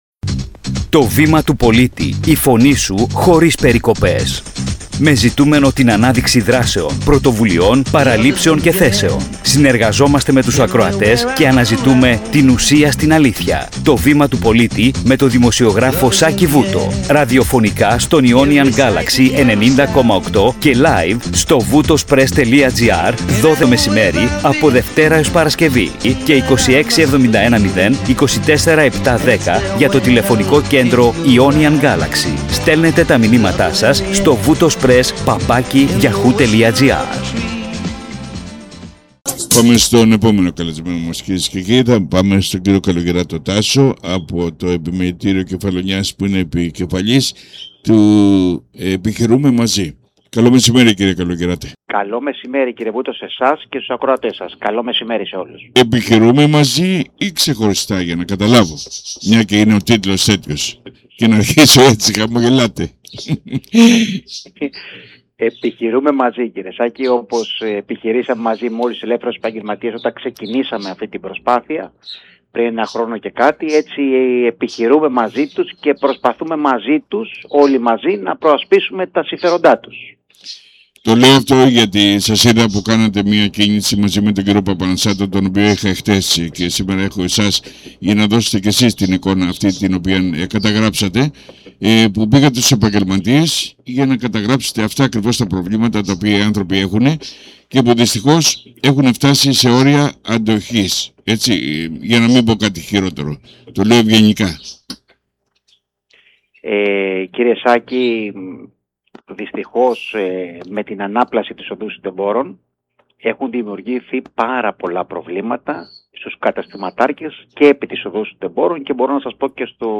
Ακούστε ολόκληρη τη συνέντευξη. audioart Ερώτηση